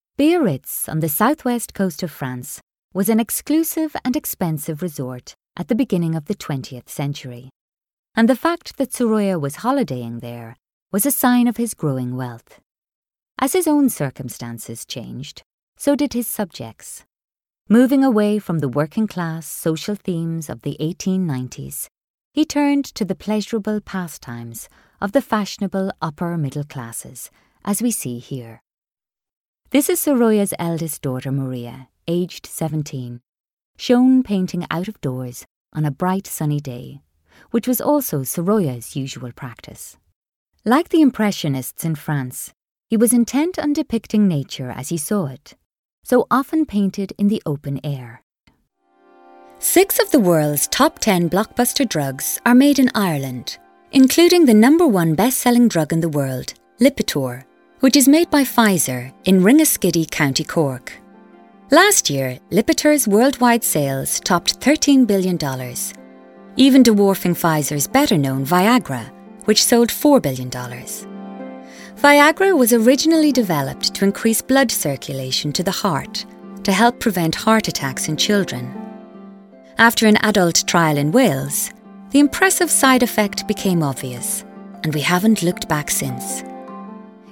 Playing age: 30 - 40s, 40 - 50sNative Accent: IrishOther Accents: Irish
• Native Accent: Irish